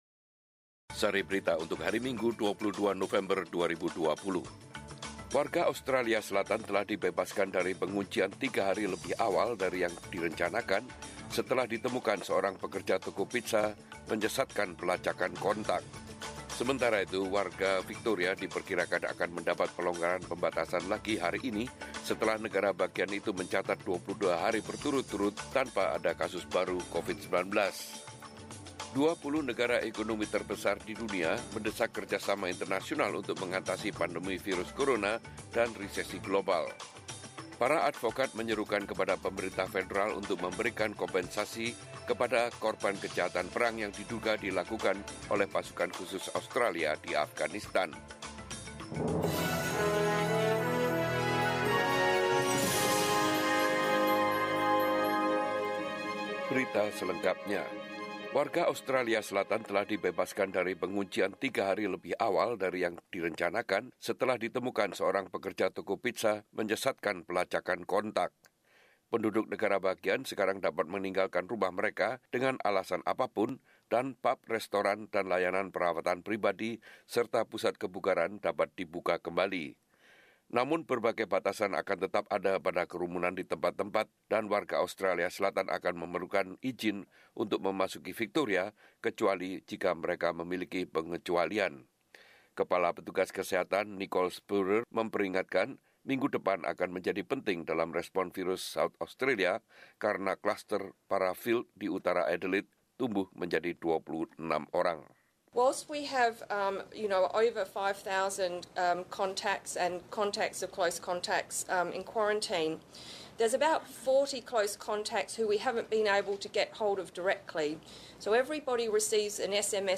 Warta Berita Radio SBS Program Bahasa Indonesia - 22 November 2020